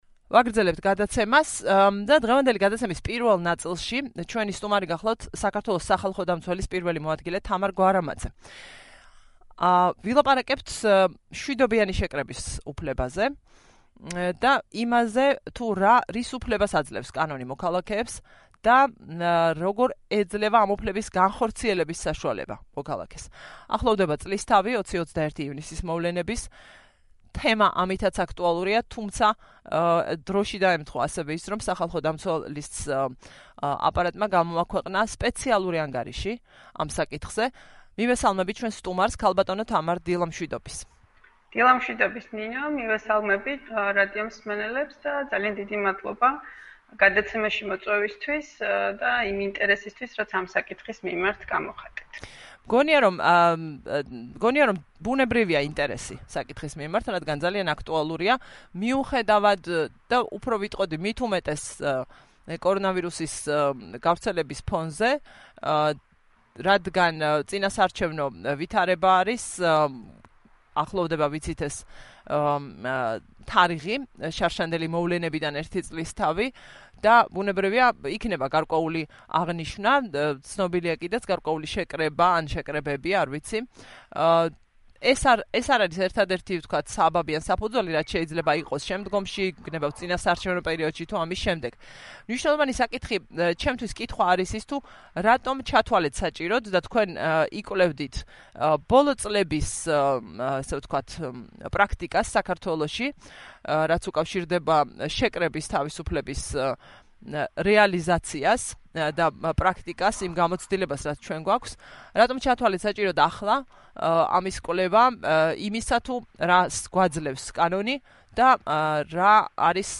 სახალხო დამცველი თვლის, რომ შეკრების თავისუფლების რეალიზაციის მხრივ არსებობს ხარვეზები როგორც საკანონმდებლო, ისე პრაქტიკის კუთხით. სახალხო დამცველის პირველმა მოადგილემ თამარ გვარამაძემ რადიო თავისუფლების ეთერში ილაპარაკა იმ ძირითად მიგნებებზე, რაც გამოიკვეთა სპეციალურ ანგარიშზე მუშაობისას.